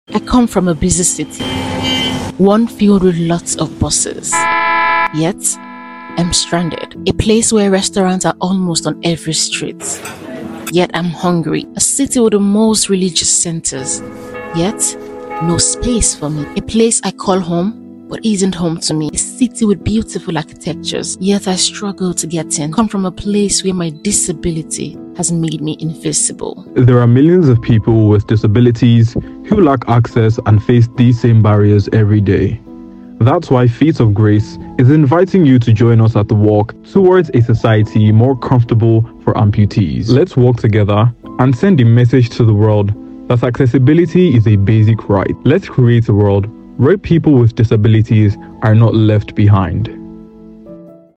PLAY RADIO AD